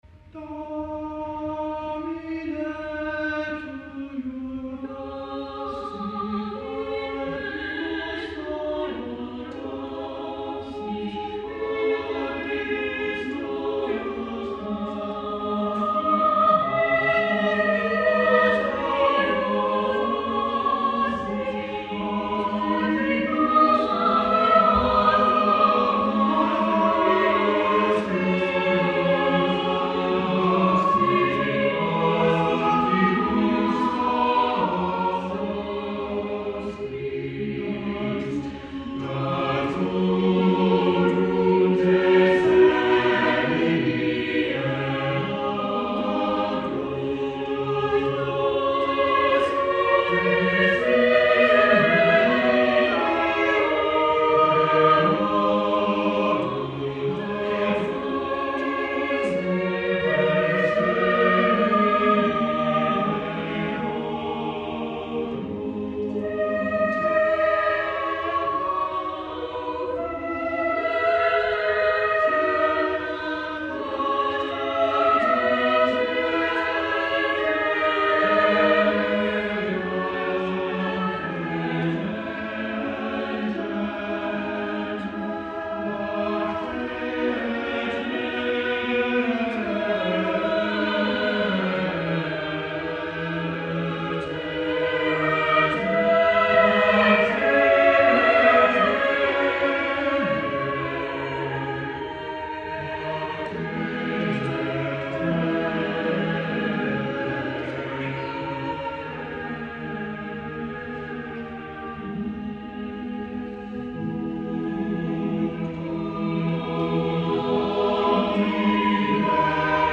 This five-part motet is from the ‘Liber primus sacrorum cantionem’ of 1589.